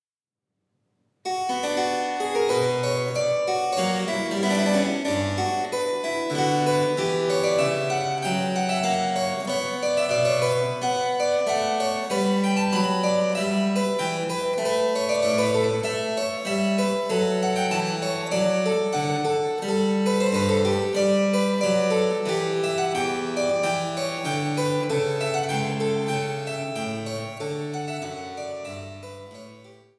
Cembalo